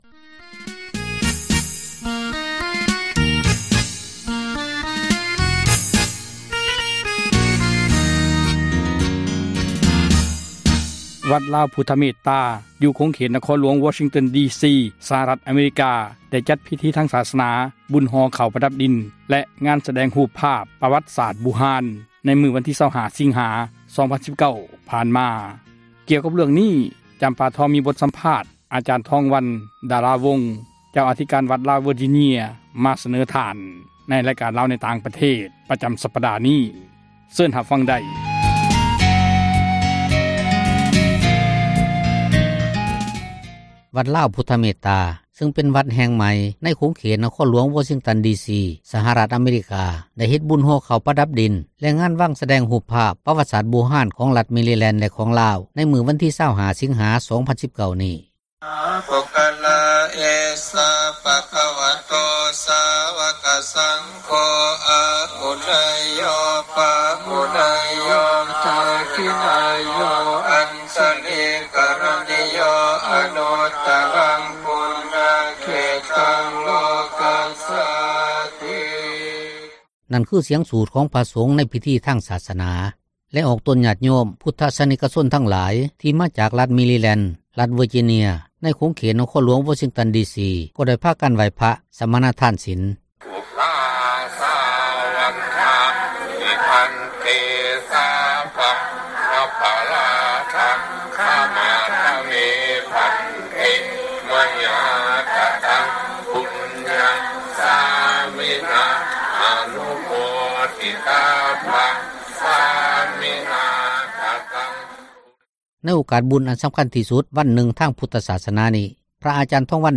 ມີບົດສຳພາດ